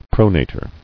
[pro·na·tor]